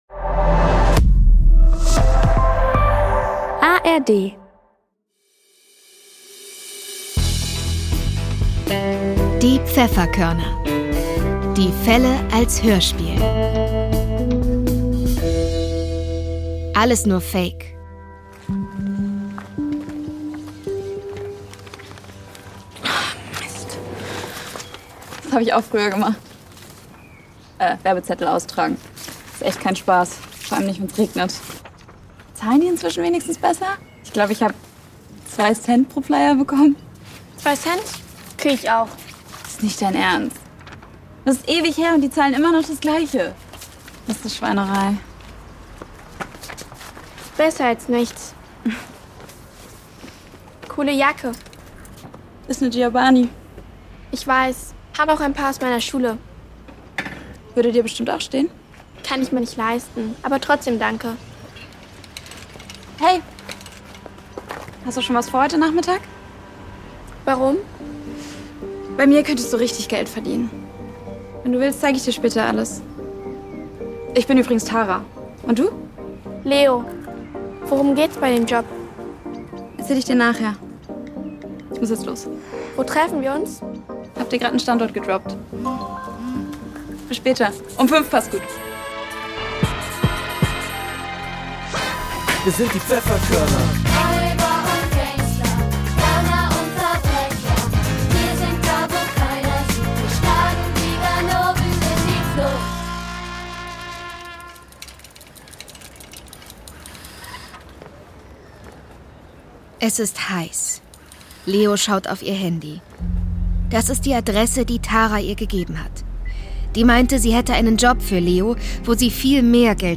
Alles nur Fake (8/26) ~ Die Pfefferkörner - Die Fälle als Hörspiel Podcast